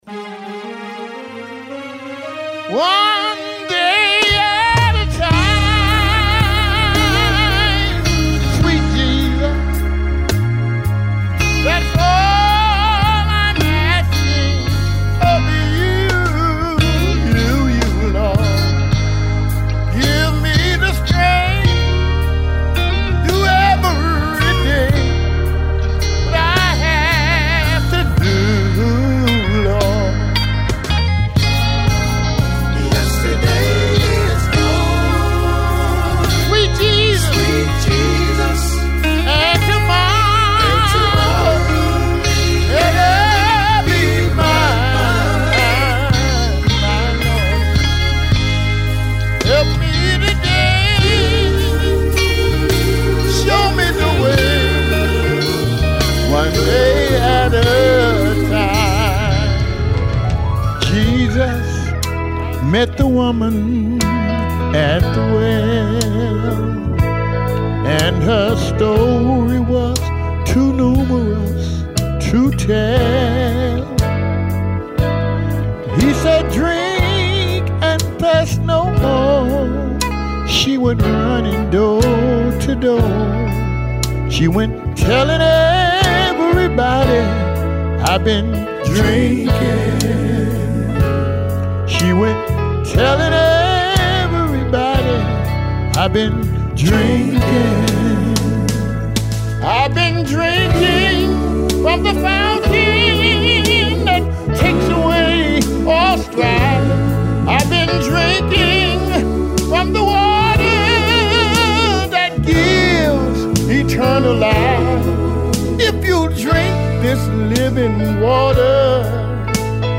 (MEDLEY)